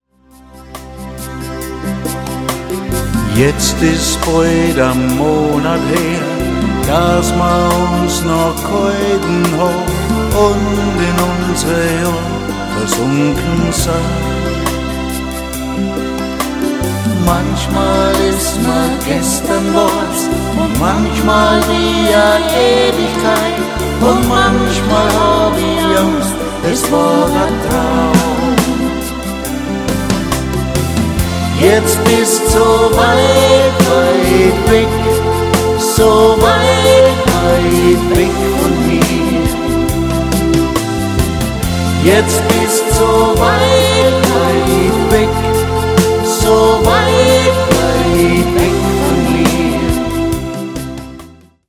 AUSTRO POP